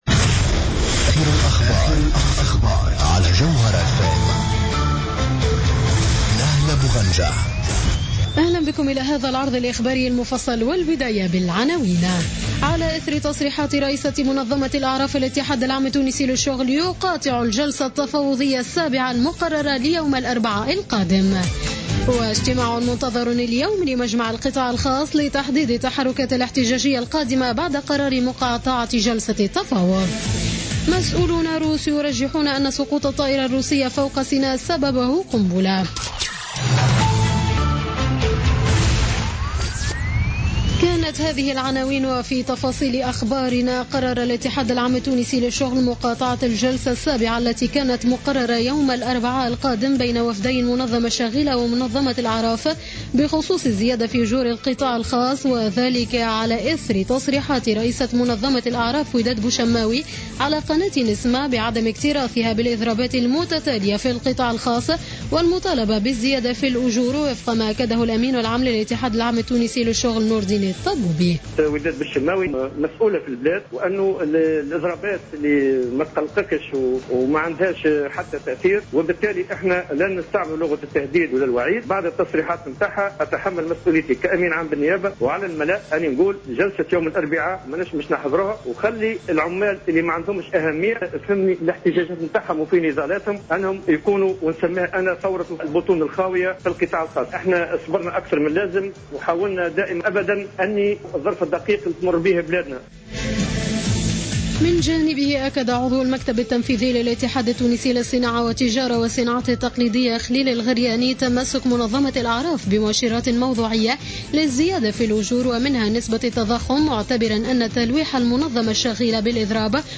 نشرة أخبار منتصف الليل ليوم الاثنين 9 نوفمبر 2015